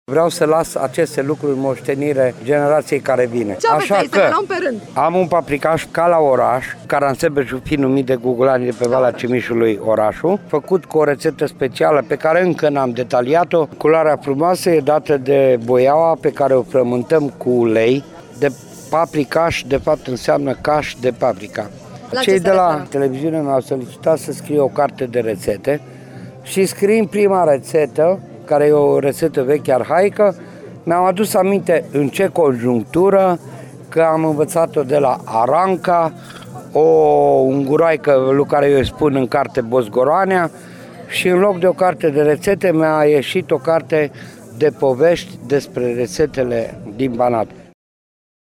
piata gugulanilor“Toamna la gugulani” continuă la Caransebeş.